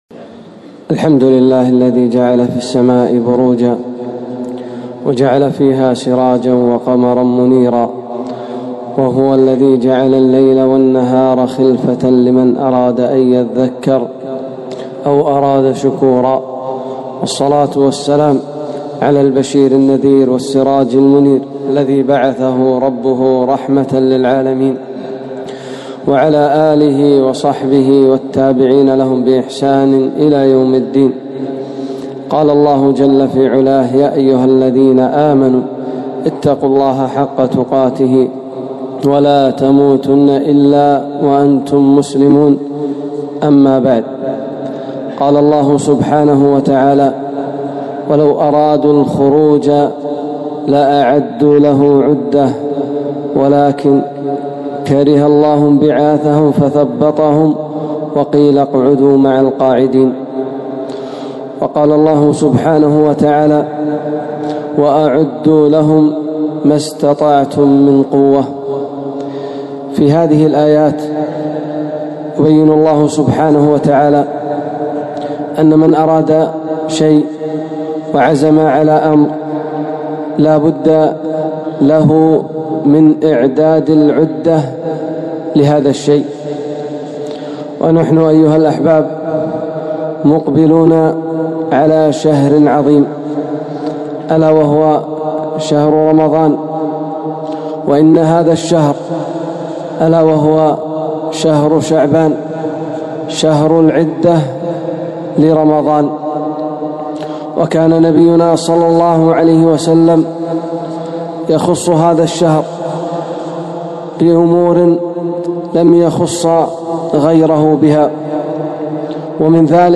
خطبة - فضل صيام شهر شعبان